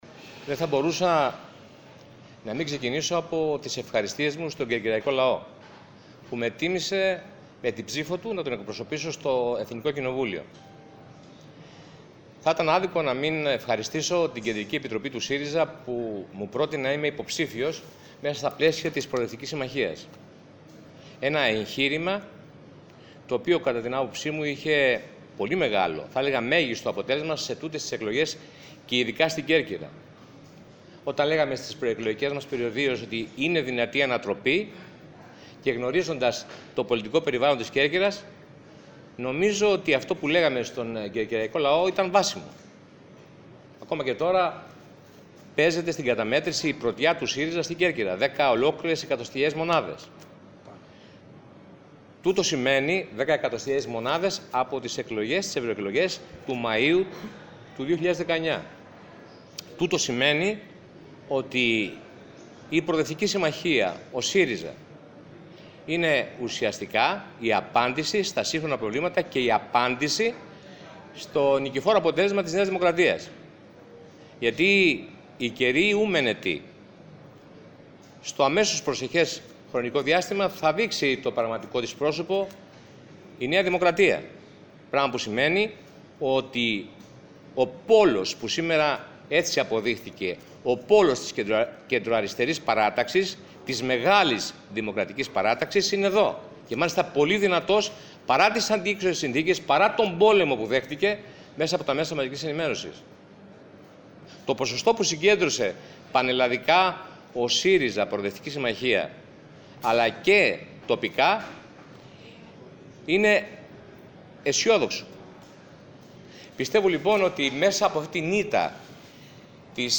Ο Αλέκος Αυλωνίτης, δεν έκρυψε το γεγονός ότι το αποτέλεσμα αποτέλεσε μια ευχάριστη έκπληξη και για τον ίδιο, ευχαρίστησε εκτός από τους συνεργάτες του, την Κ.Ε. του ΣΥΡΙΖΑ που επέλεξε να τον συμπεριλάβει στο ψηφοδέλτιο και σημείωσε ότι η επόμενη ημέρα, βρίσκει το ΣΥΡΙΖΑ έτοιμο να αναλάβει το ρόλο της υπεύθυνης αξιωματικής αντιπολίτευσης ενώ αναγκαία χαρακτήρισε και την οργανωτική του αναδόμηση.